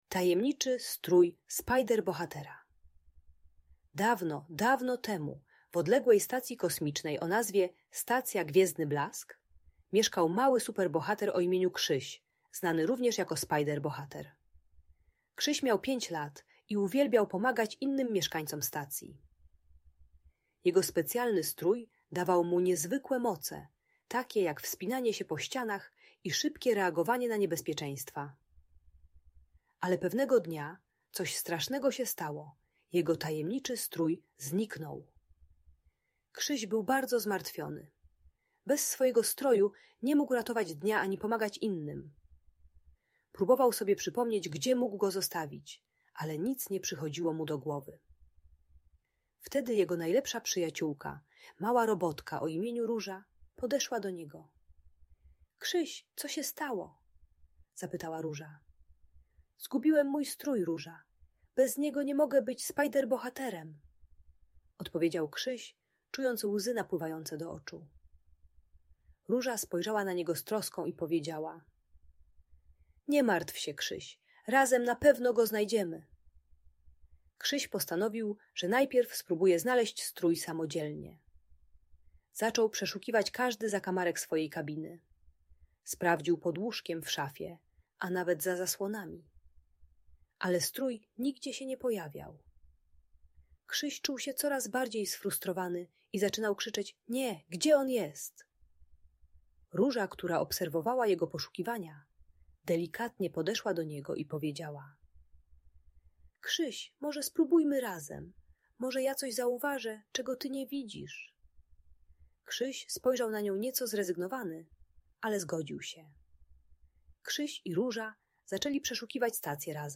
Tajemniczy Strój Spider-Bohatera - Audiobajka